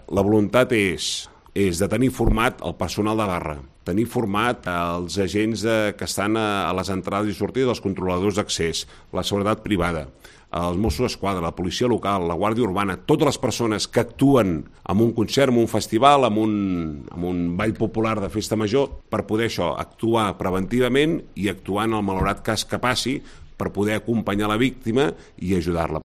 Declaraciones del Consejero Miquel Buch